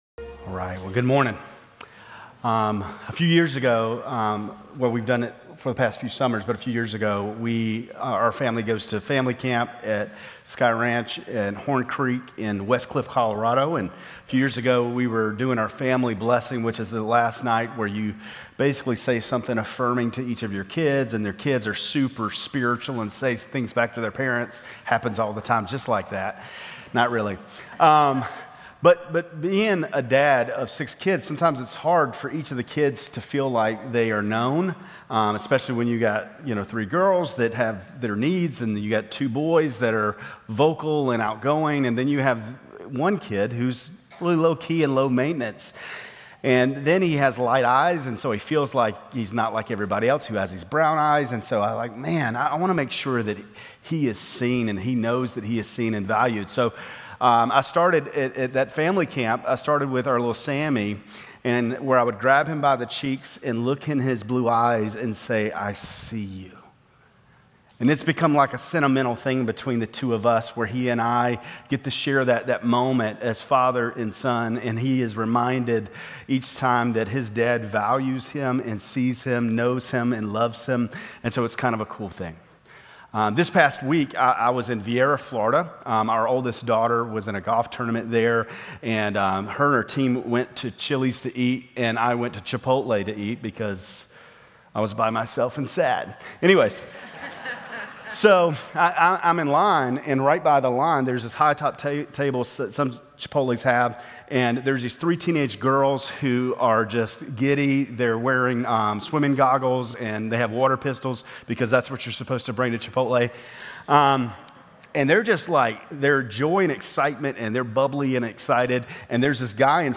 Grace Community Church Lindale Campus Sermons 4_20 Lindale Campus Apr 20 2025 | 00:26:35 1x 00:00 / 00:26:35 Subscribe Share RSS Feed Share Link Embed